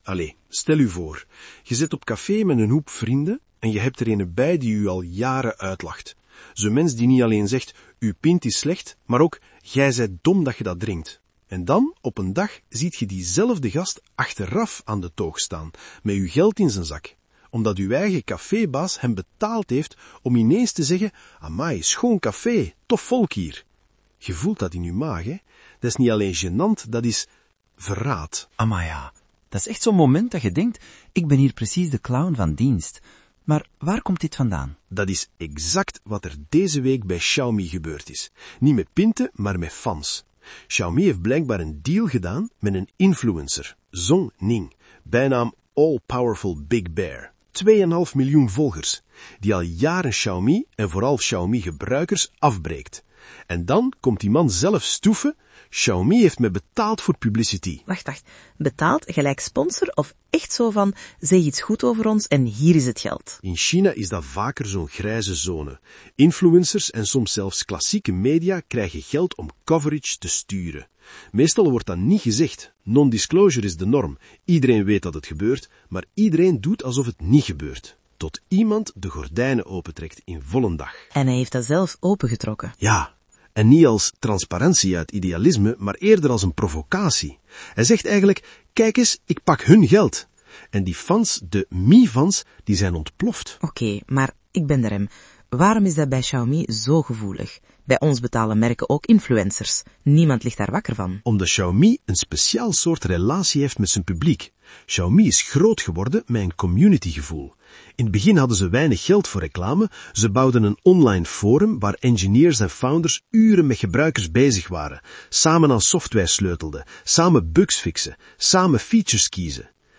… continue reading 17 episodes # Tech # AIgenerated Podcast